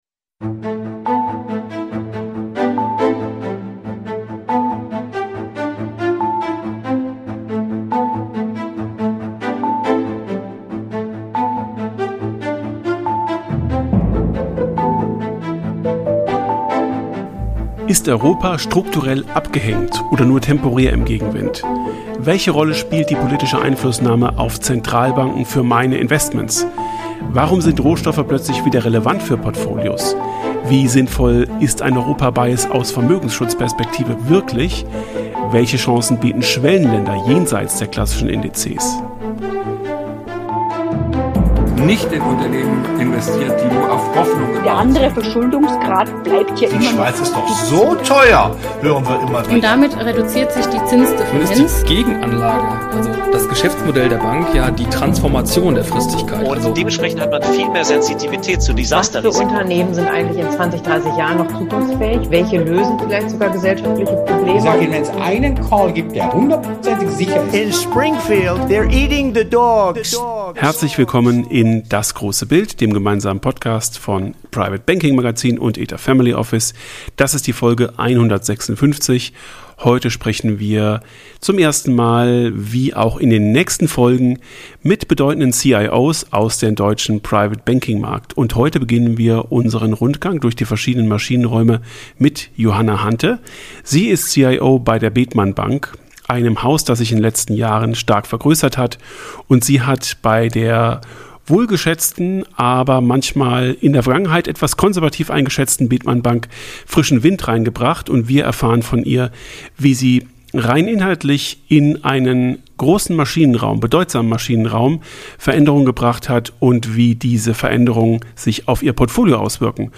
Folge 156: Power of Compounding – CIO-Gespräch